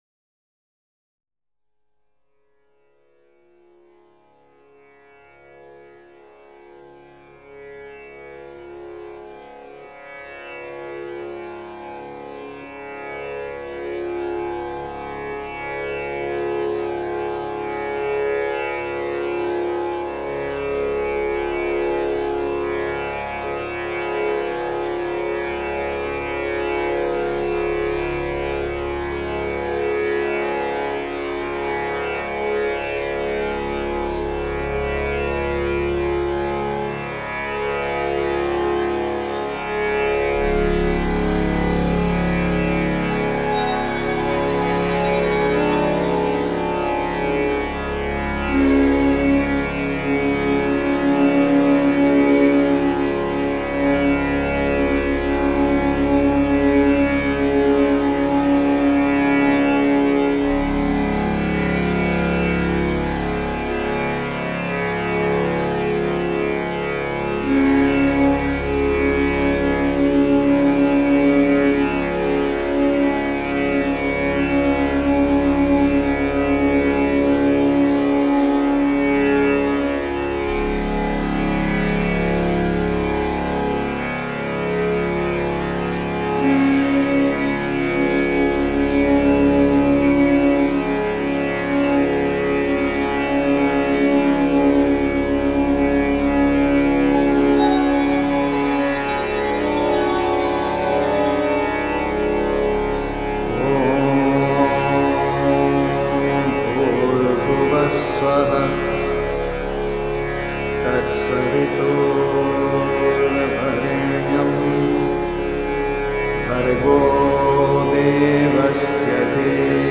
Here the function of harmony, provided by the tamboura drone, is to provide a reference point above which the singers and instrumentalists perform elaborate improvisations and meditation-like doublings of the drone tone.
Function of harmony by tamboura drone
Elaborate improvisations
Doublings of the drone tone